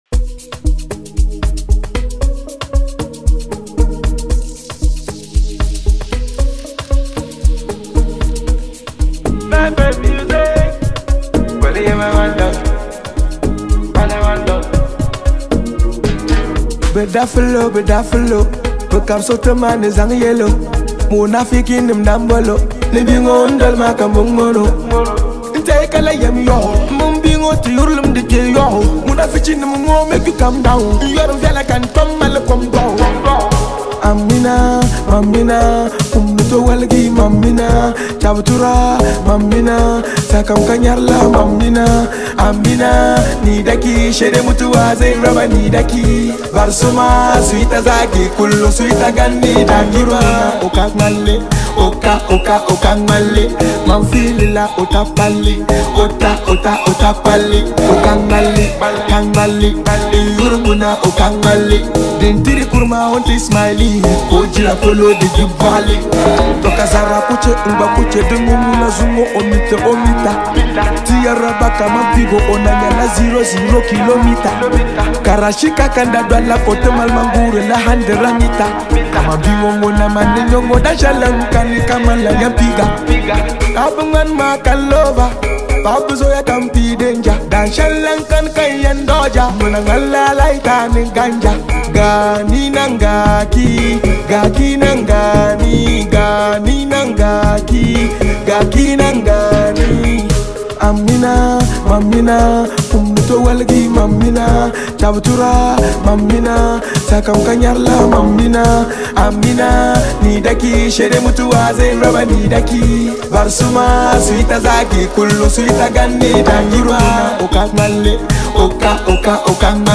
highly celebrated Hausa Singer
it comes with a lot of energy and positive Vibes